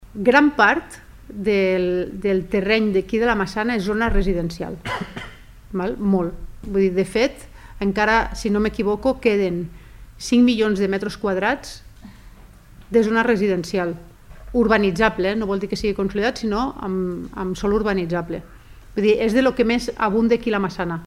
La cònsol major, Eva Sansa, ha defensat que la mesura respon a la voluntat d’“apostar per un model de baixa densitat per guanyar en seguretat, eficiència de les xarxes i qualitat paisatgística.